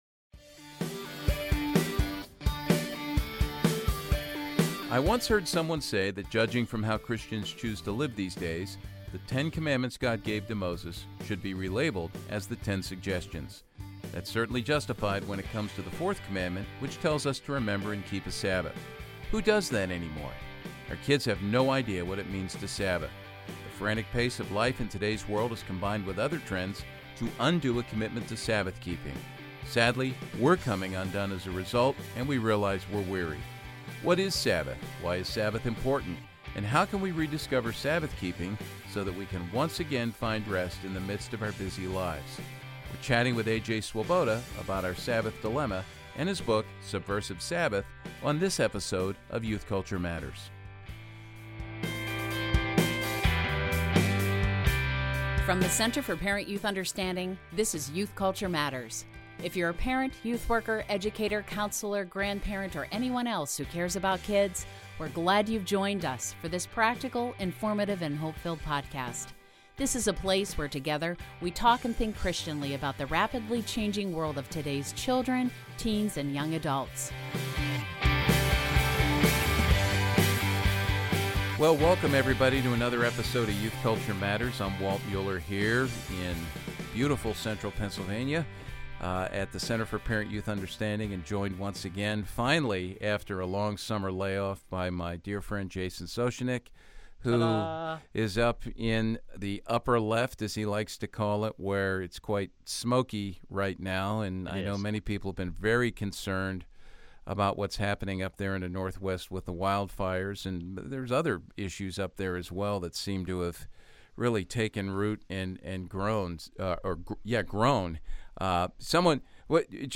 And how can we leverage these cultural artifacts in our ministries with kids? Today, we have fun with the lively and winsome trio from the Thanos to Theos podcast, about Superheroes, comic books, movies, theology, and our kids, on this episode of Youth Culture Matters.